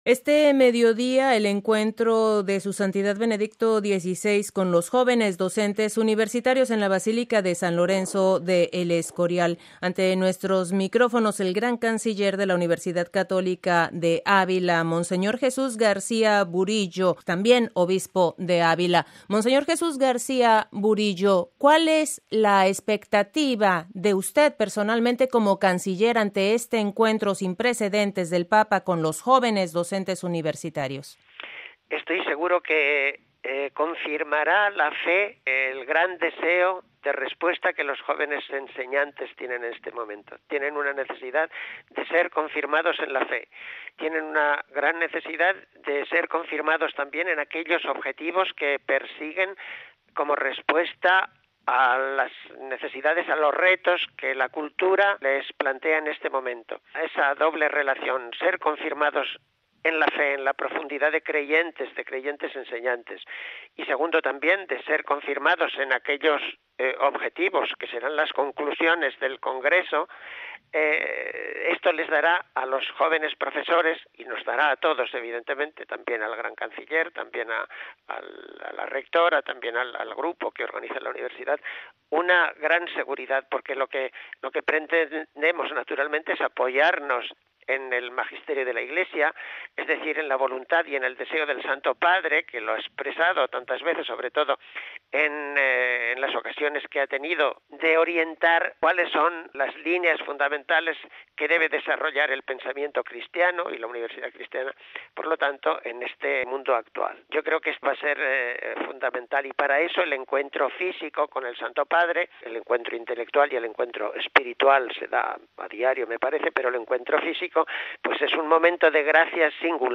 Mons. Jesús García Burillo, Obispo de Ávila estuvo presente en este encuentro. Antes de su participación en tan singular encuentro de los jóvenes profesores universitarios con el Sucesor de Pedro Conversamos con él para conocer desde su perspectiva las esperanzas que animan a las Universidades Católicas, recordamos que del 12 al 14 de agosto se celebró en la Universidad Católica de Ávila el Congreso Mundial de Universidades Católicas en preparación para el encuentro de hoy.